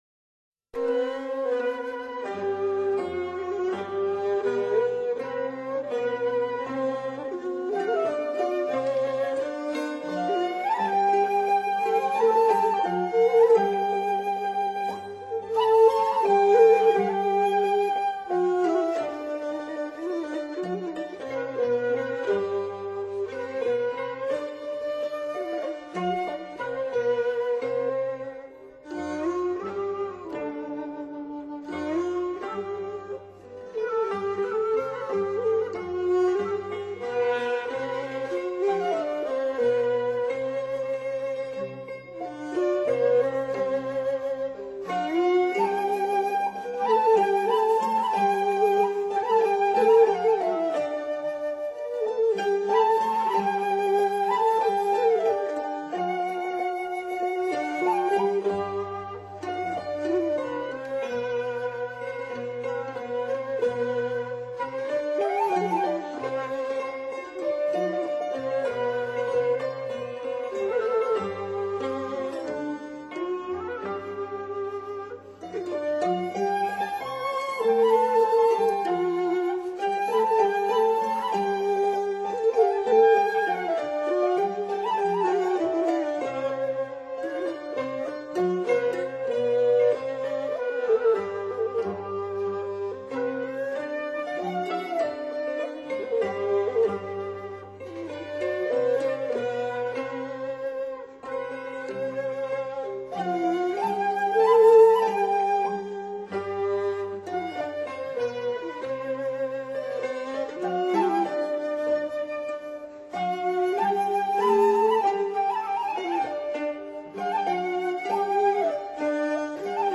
广东音乐
广东音乐是一种标题音乐，结构上以简驭繁。
高胡、二弦、小提琴
小提琴、秦琴、阮
洞箫、笛子、扬琴、阮
木琴、三弦、阮
中胡、椰胡
喉管